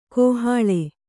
♪ kōhāḷe